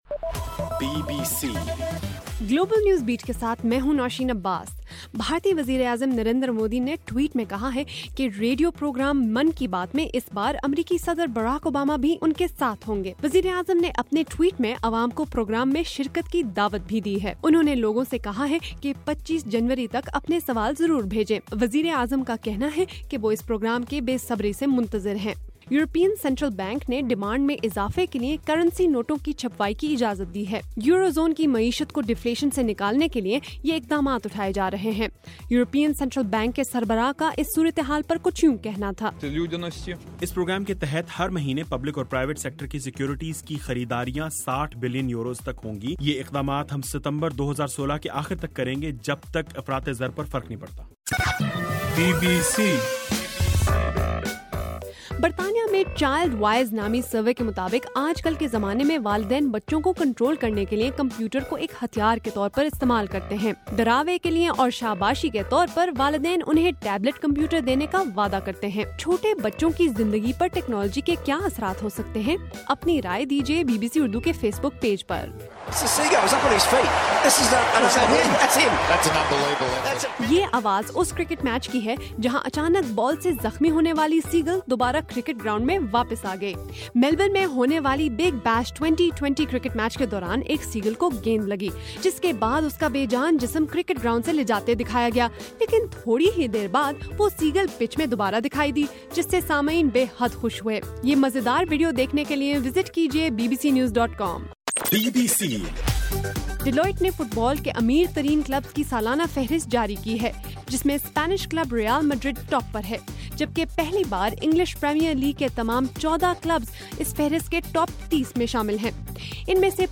جنوری 22: رات 10 بجے کا گلوبل نیوز بیٹ بُلیٹ